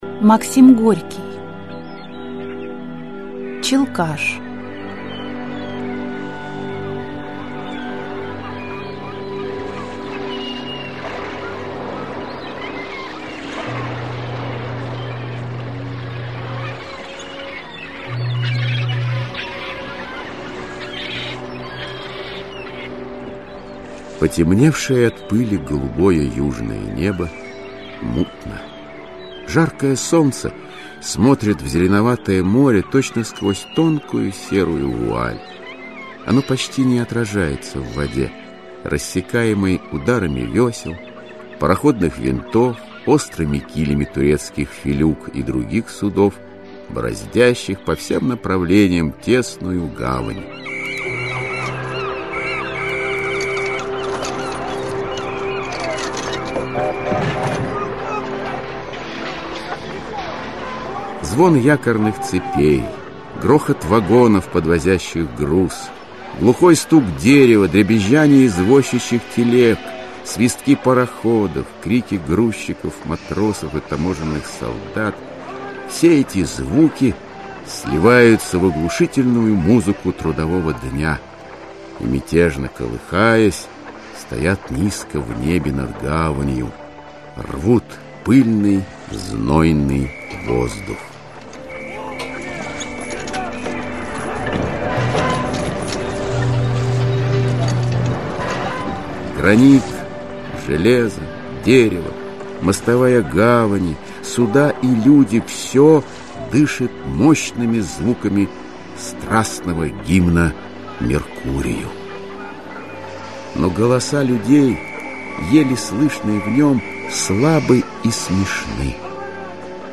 Аудиокнига Челкаш из жанра Классическая проза - Скачать книгу, слушать онлайн